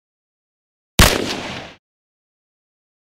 دانلود صدای شلیک گلوله ژسه از ساعد نیوز با لینک مستقیم و کیفیت بالا
جلوه های صوتی
برچسب: دانلود آهنگ های افکت صوتی اشیاء دانلود آلبوم صدای ژسه یا ژ3 از افکت صوتی اشیاء